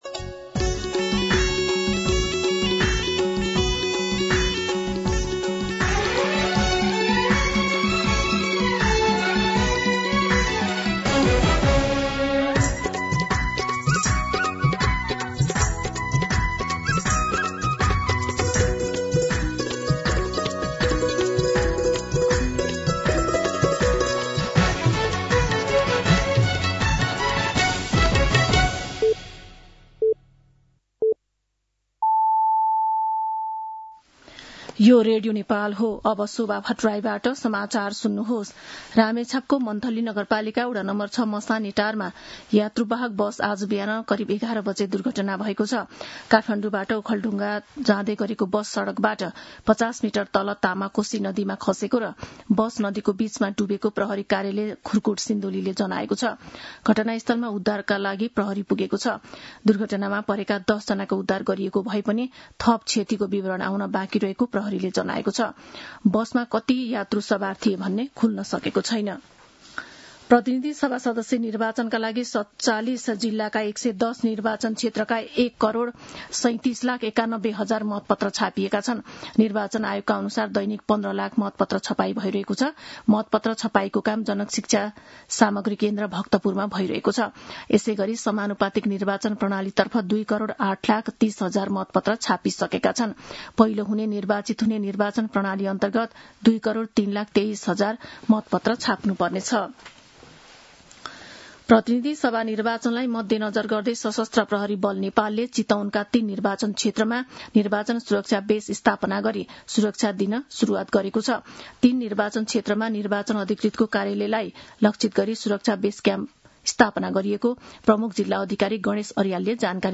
दिउँसो १ बजेको नेपाली समाचार : २७ माघ , २०८२
1pm-News-10-27.mp3